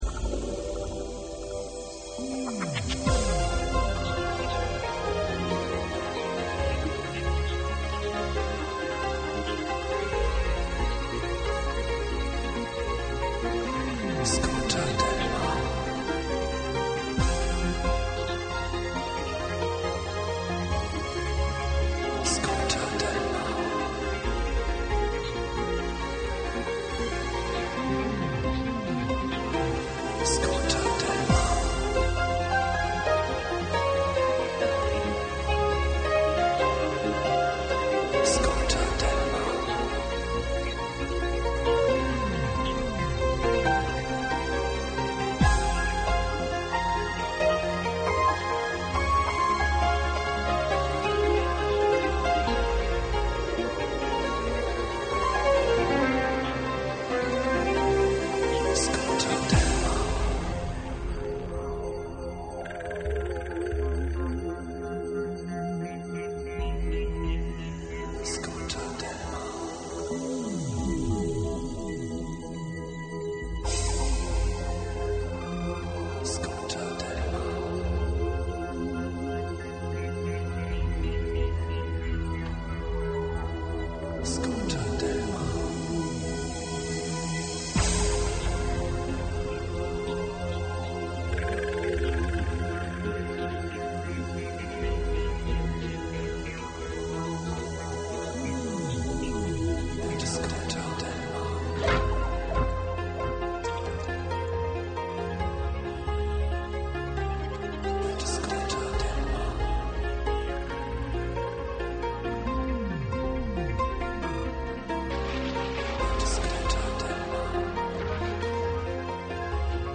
• Quality: 44kHz, Stereo